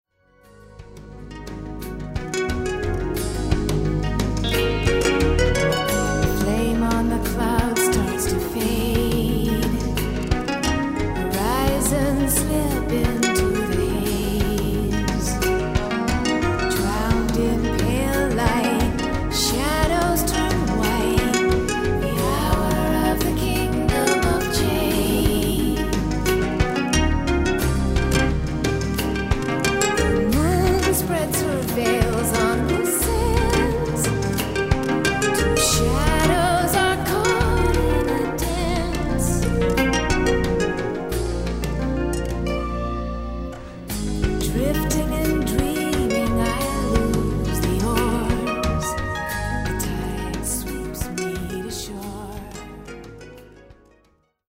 Recorded & mixed at Powerplay Studios, Maur – Switzerland